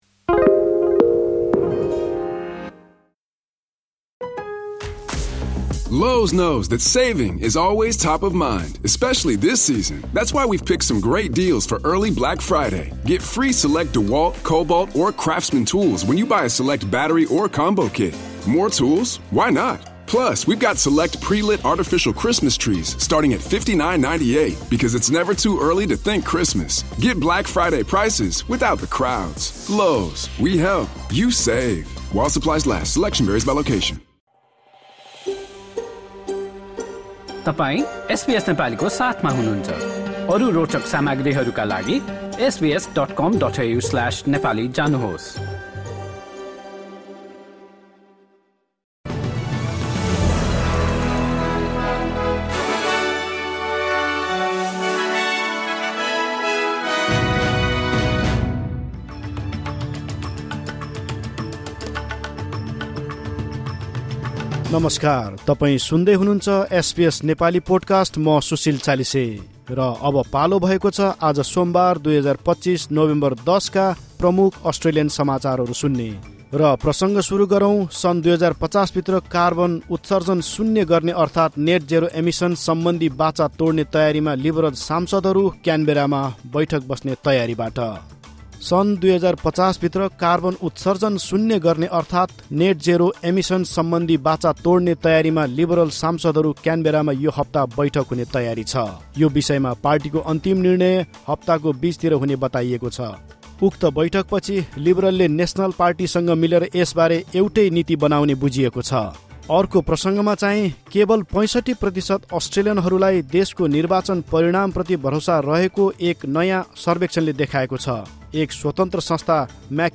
एसबीएस नेपाली प्रमुख अस्ट्रेलियन समाचार: मङ्गलवार, ११ नोभेम्बर २०२५